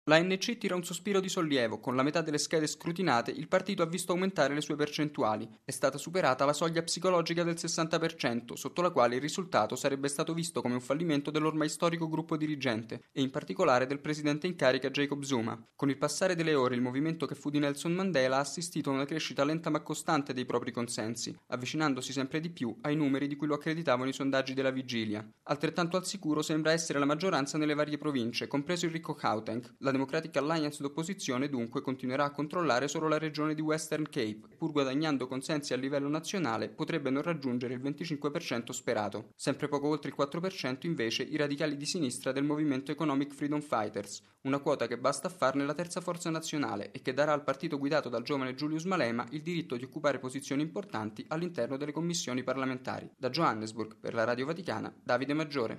Da Johannesburg